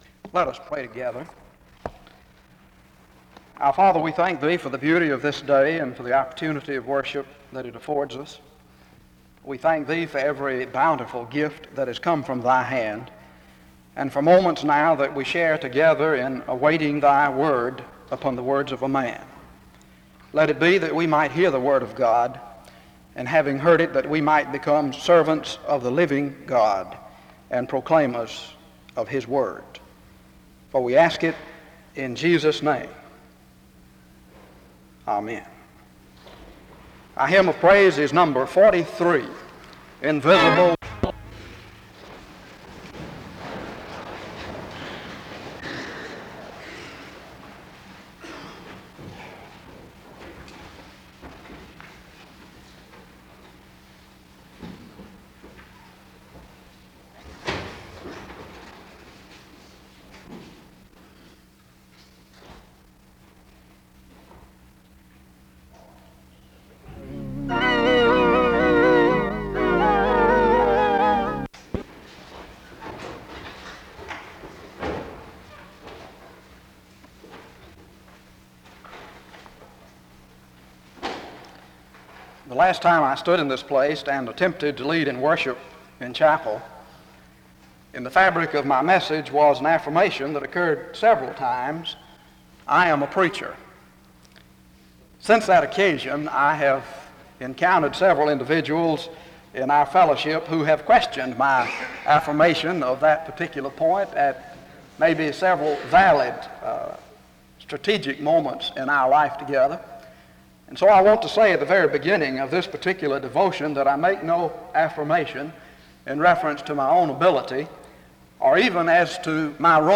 The service begins with a prayer (0:00-0:37).
He closes in prayer (19:47-20:31).